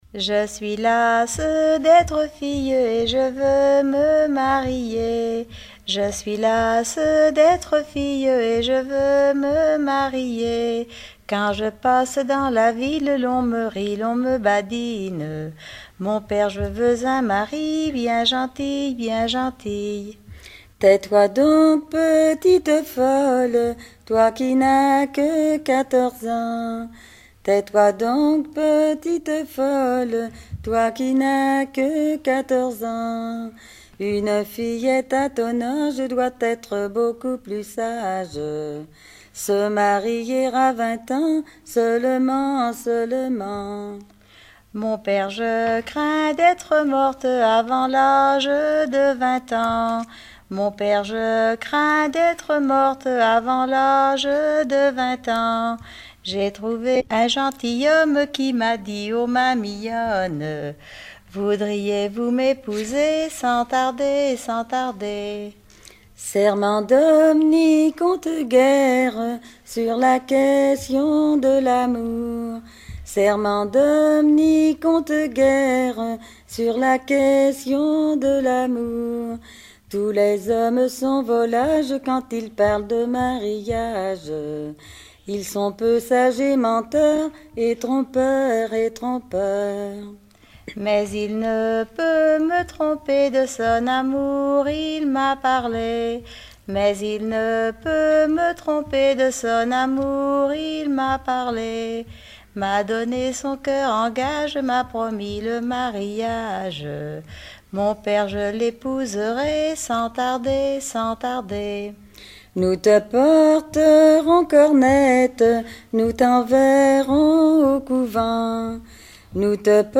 Genre dialogue